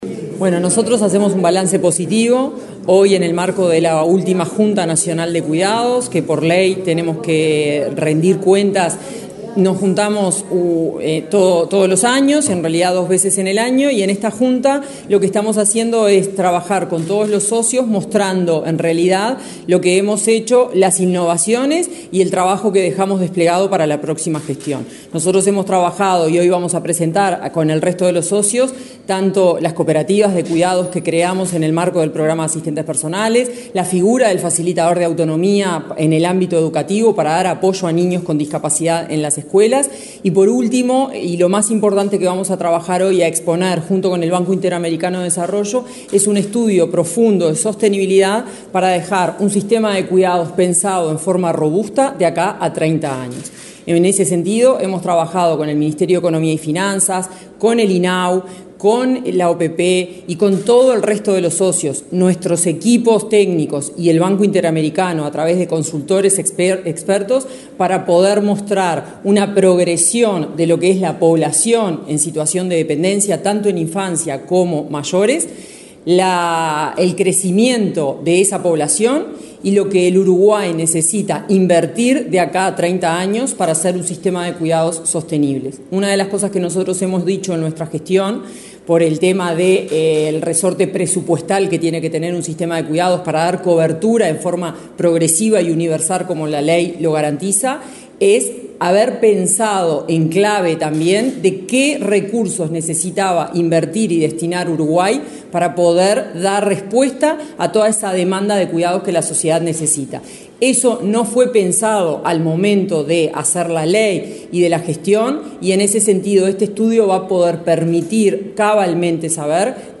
Entrevista a la directora nacional de Cuidados, Florencia Krall | Presidencia Uruguay